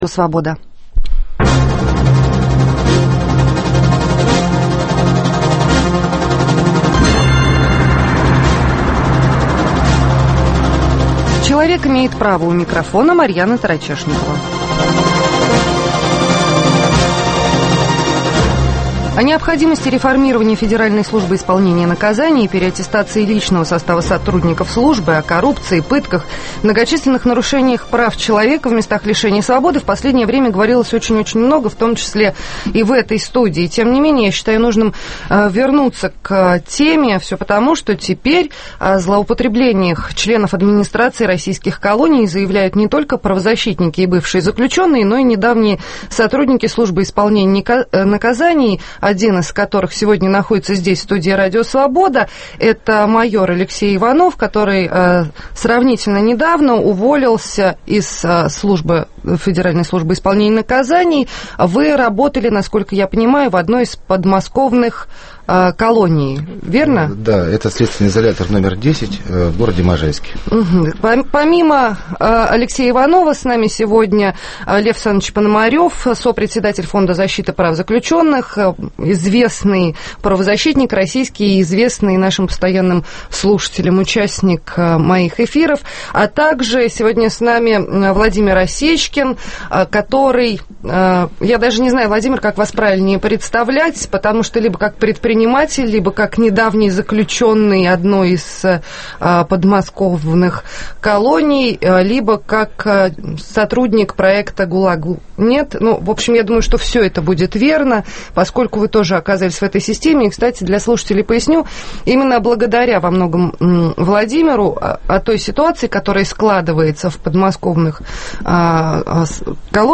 О коррупции, торговле наркотиками и злоупотреблениях членов администраций российских колоний теперь заявляют не только правозащитники и бывшие заключенные, но и недавние сотрудники службы исполнения наказаний. В студии РС Сопредседатель Фонда защиты прав заключенных Лев Пономарев